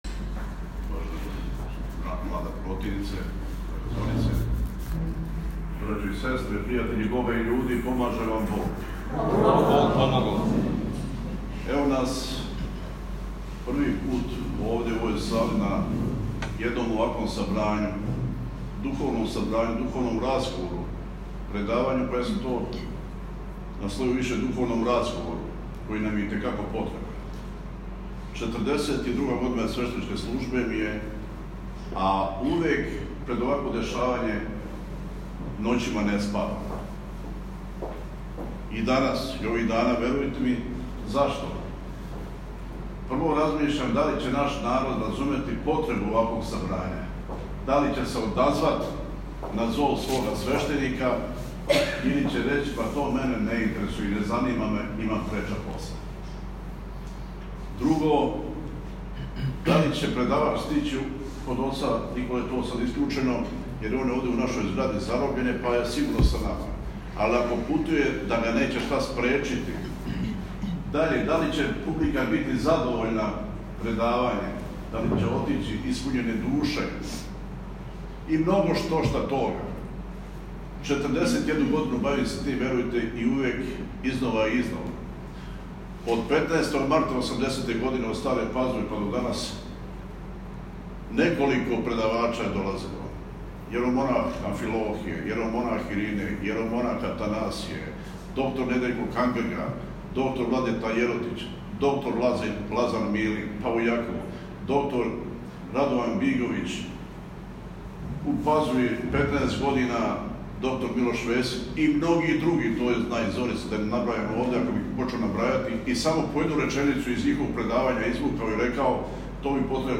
Предавање на тему: „Вера наша, вера славна, наша вера православна“ одржано је  у сали парохијског дома храма Светих Кирила и Методија у Бусијама.
уводна реч: